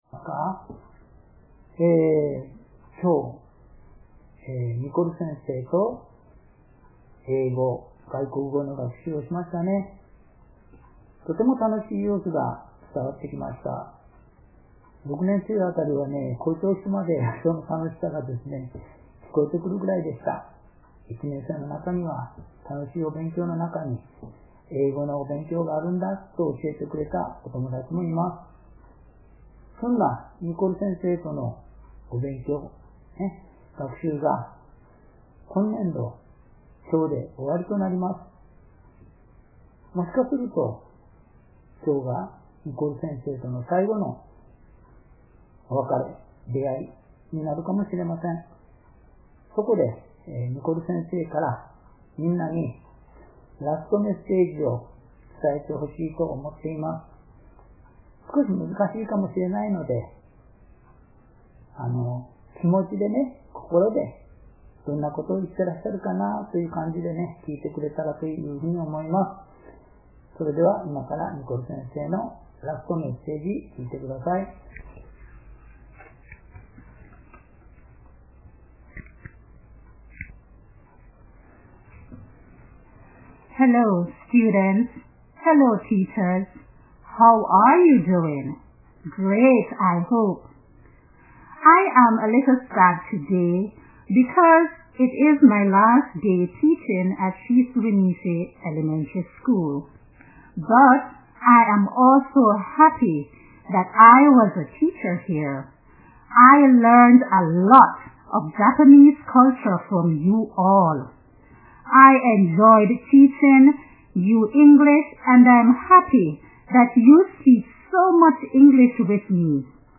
お言葉を録音させていただきましたが、操作ミスで私の声も入っています。しかも、終わってから1分くらい、電源を切るのを忘れていました。
それでは、給食時間中に伝えていただいた「お別れの言葉」をお伝えします。